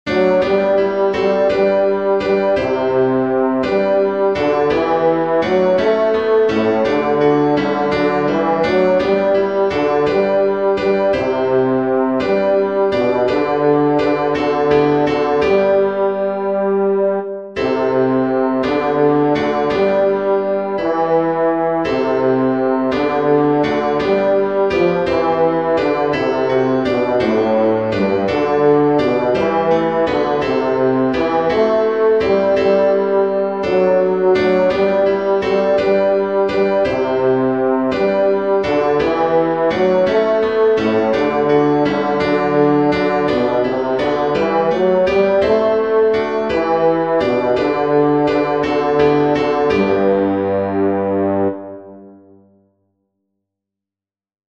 venez_divin_messie-bass.mp3